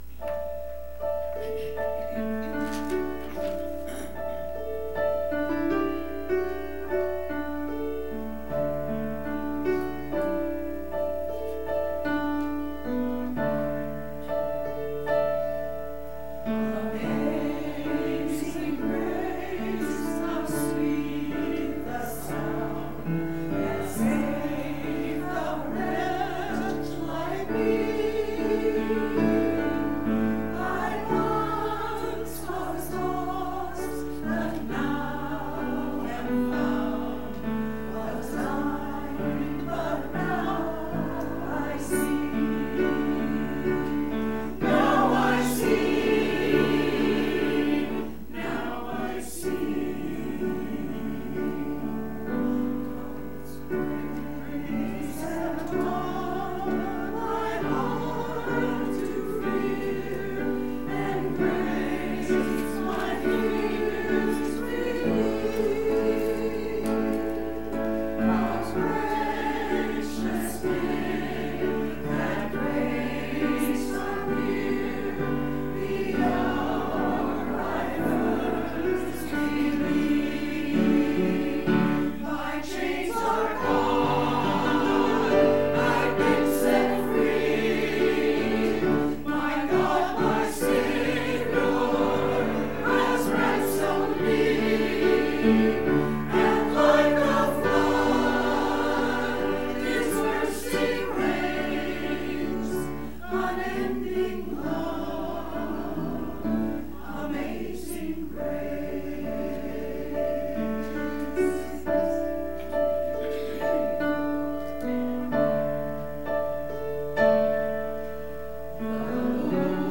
Church Choir – Amazing Grace 10.14.18
To hear the church choir praise God with Amazing Grace please click play below.